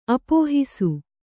♪ apōhisu